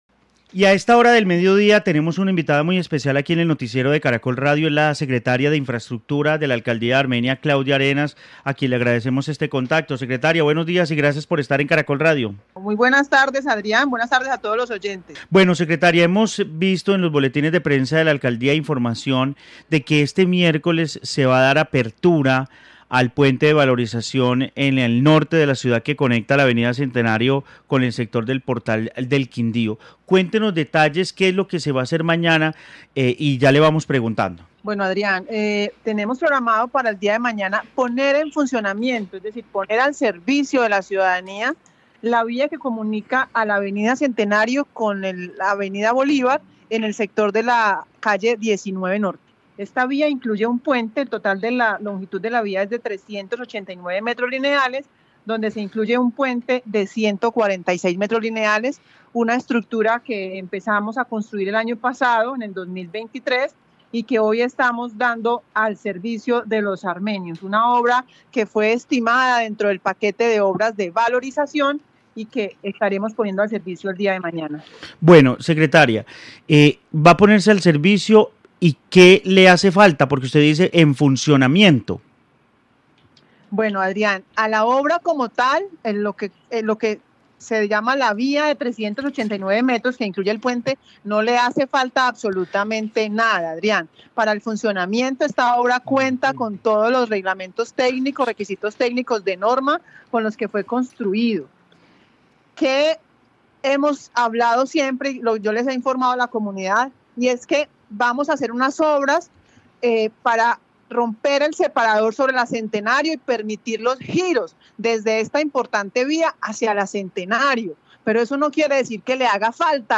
Claudia Arenas, secretaría de infraestructura de Armenia
En el noticiero del mediodía de Caracol Radio Armenia hablamos con la secretaría de infraestructura de la ciudad, Claudia Arenas que entregó detalles sobre la puesta en funcionamiento el puente de valorización en la avenida 19 norte que comunicará a la avenida Centenario con el sector del Centro Comercial Portal del Quindío.